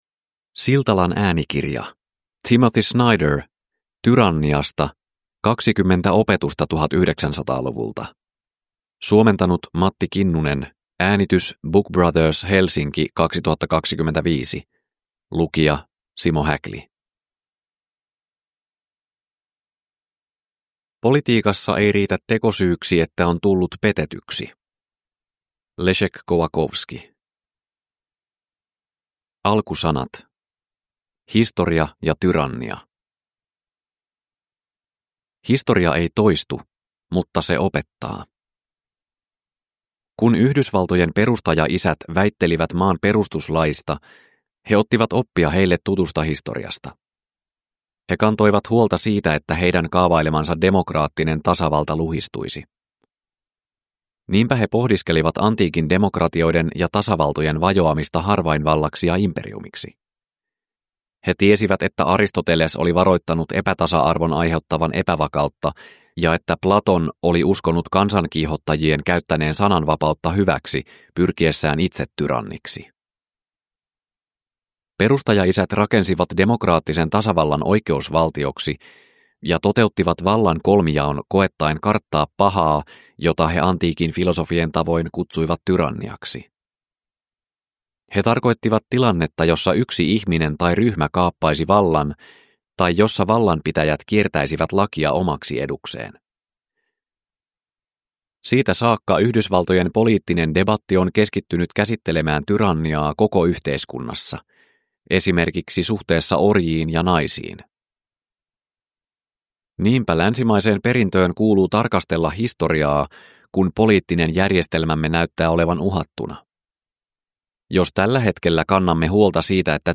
Tyranniasta – Ljudbok